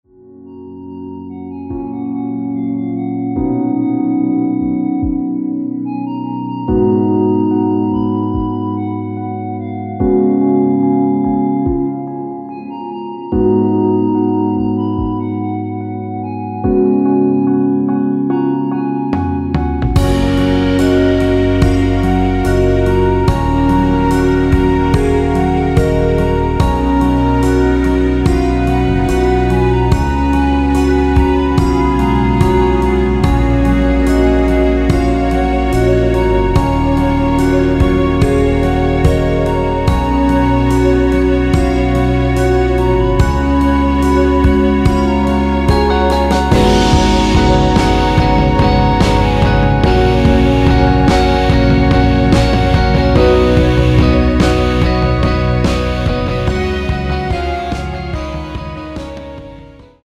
원곡 6분1초에서 4분 41초로 짧게 편곡된 MR입니다.
원키에서(+4)올린 멜로디 포함된(1절앞+후렴)으로 진행되는 MR입니다.
F#
멜로디 MR이라고 합니다.
앞부분30초, 뒷부분30초씩 편집해서 올려 드리고 있습니다.